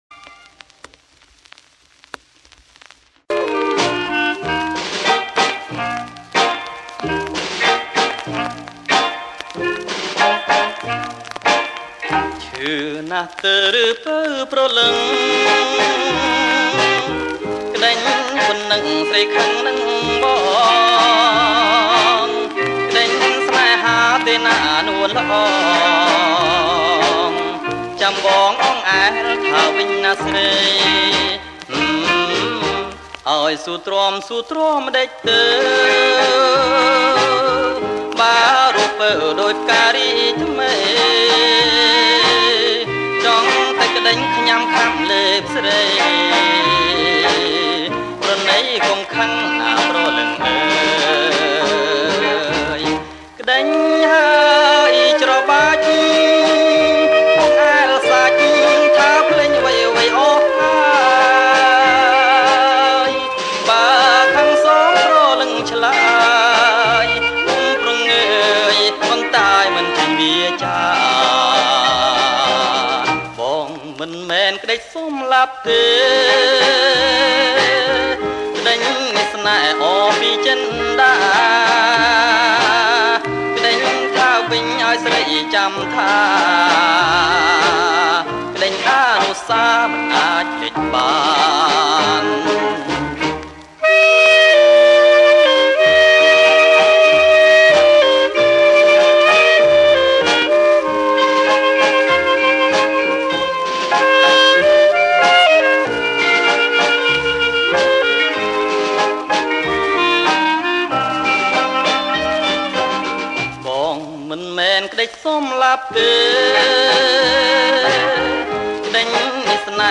• ប្រគំុជាចង្វាក់ Slow Twist